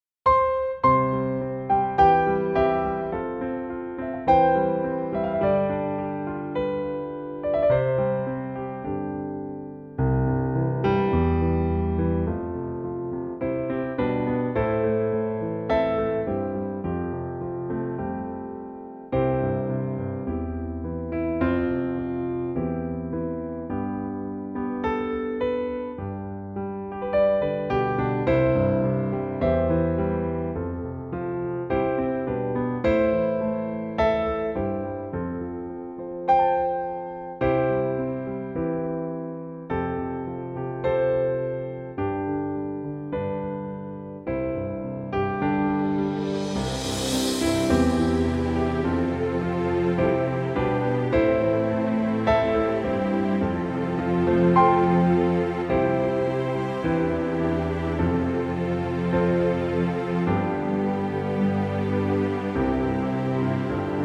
Unique Backing Tracks
original key.
key - C - vocal range - C to E
Very lovely copy arrangement of this popular Irish gem